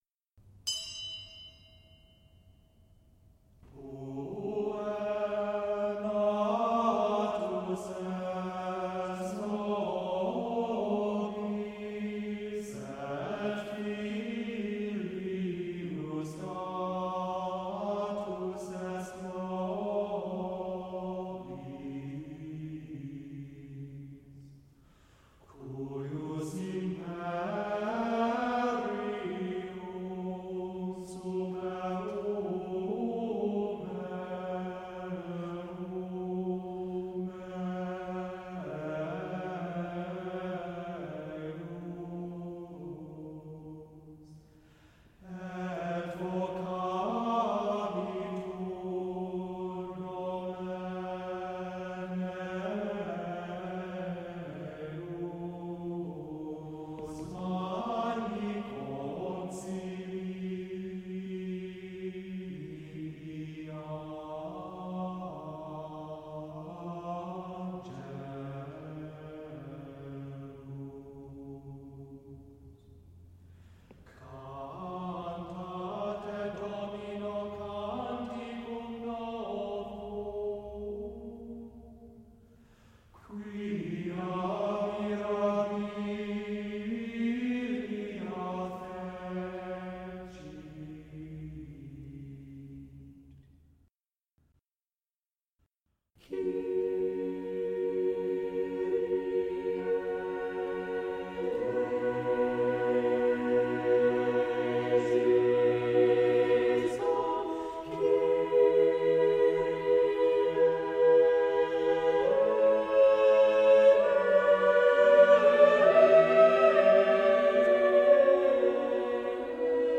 an adult mixed choir of 23 amateur singers
six-voice parody Mass
sublime polyphony